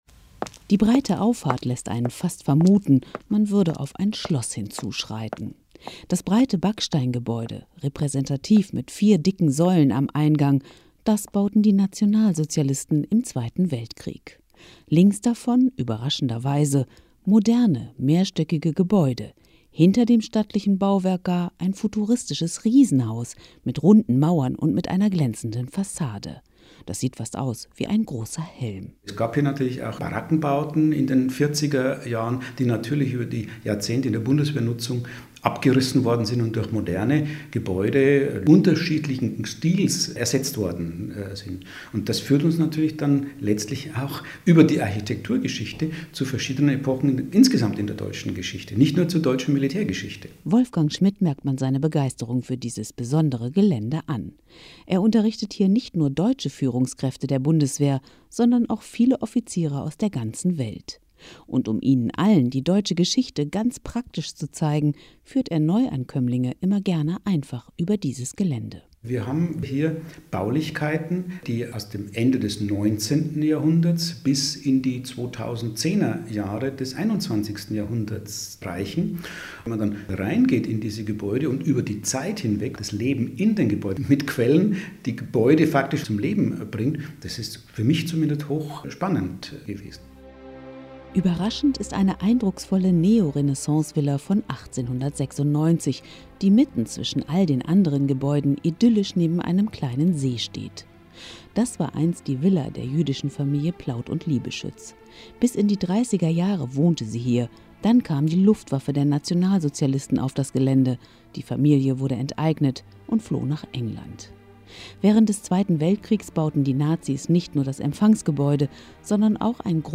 Spaziergang zum Nachhören